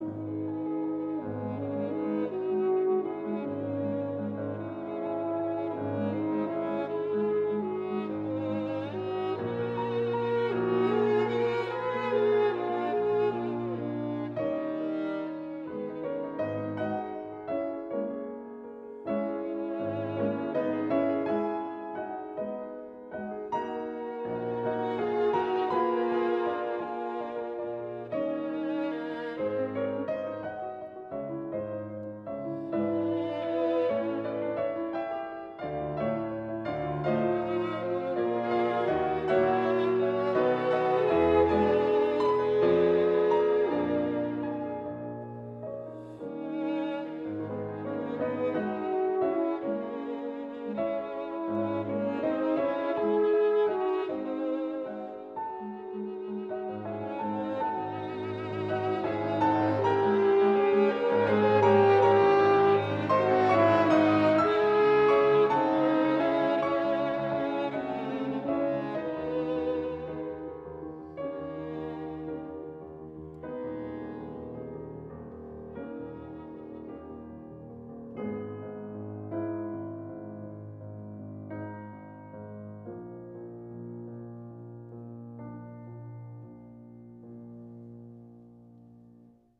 Melodie und Rhythmus werden geglättet, das Volkslied wird gewissermaßen brahmsisch veredelt, das Zitat macht sich im Brahms-Sound fast unsichtbar. Und es steht nun in Moll.
Brahms: Horntrio (Scherzo, Takt 287ff, Molto meno Allegro)